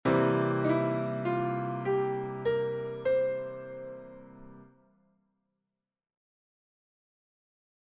Blues Piano
La 3ce3^{ce} mineure peut être utilisée comme ‘grace note’ :
grace-note.mp3